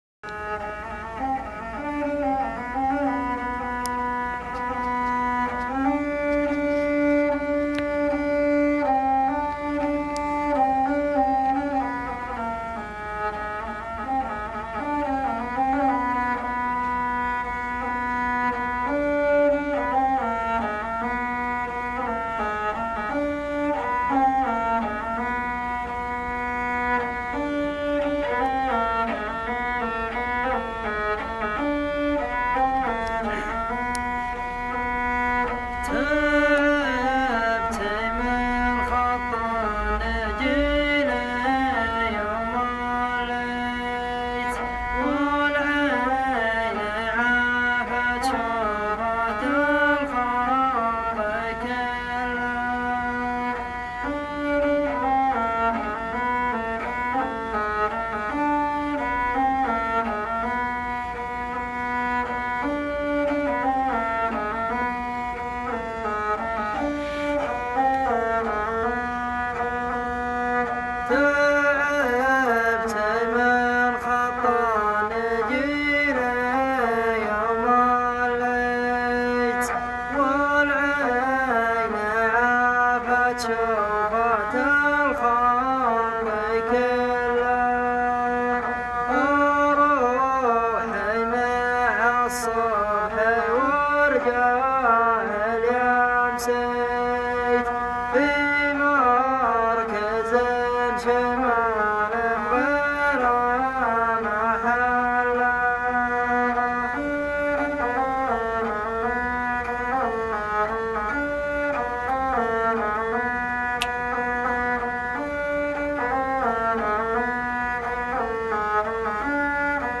Carpeta: musica arabe mp3
عازف ربابه خطيررررررر